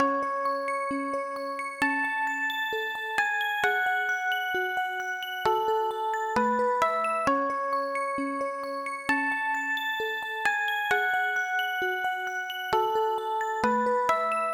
SB-  dro arp.wav